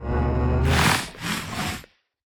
Minecraft Version Minecraft Version snapshot Latest Release | Latest Snapshot snapshot / assets / minecraft / sounds / mob / warden / sniff_2.ogg Compare With Compare With Latest Release | Latest Snapshot
sniff_2.ogg